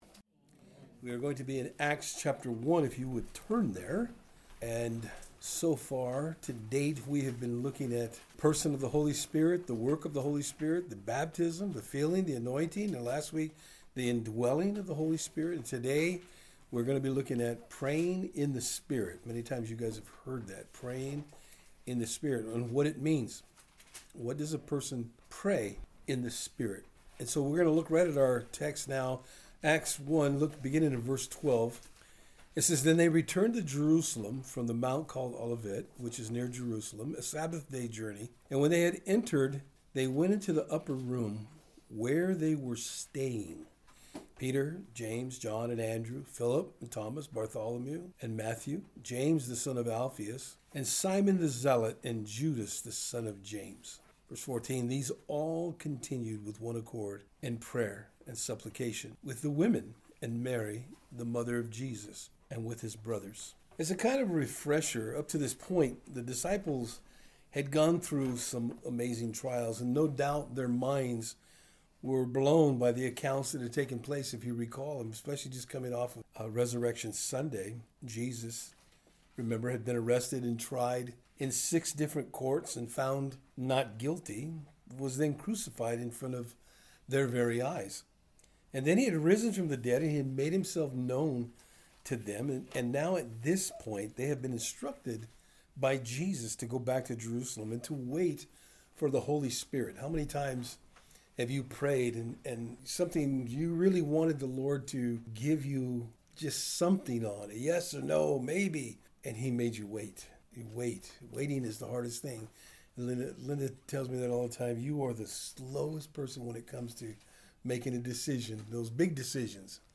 The Holy Spirit Service Type: Thursday Afternoon Today we will look at what it truly means to “Pray In The Spirit.”